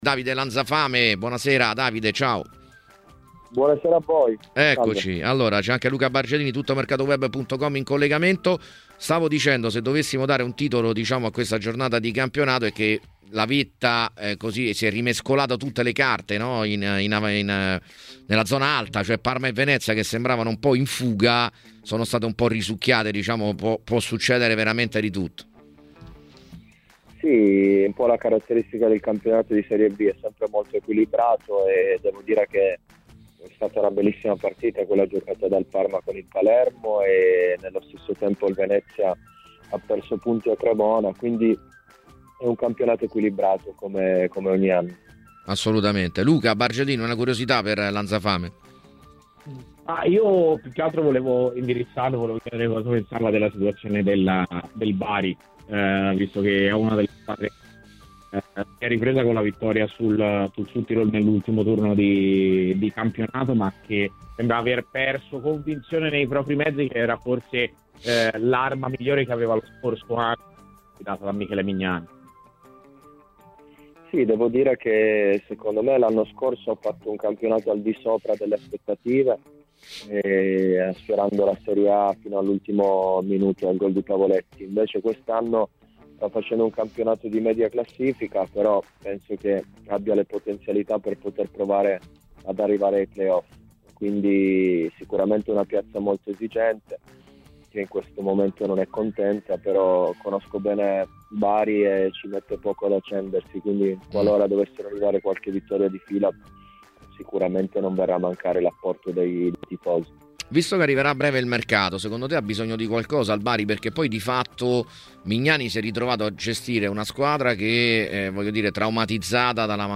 Intervenuto ai microfoni di TMW Radio durante la trasmissione "Piazza Affari", l'ex attaccante di Parma e Palermo Davide Lanzafame ha commentato, tra gli altri temi, il match di ieri giocatosi allo stadio Tardini: "Quella tra Parma e Palermo è stata una bella partita.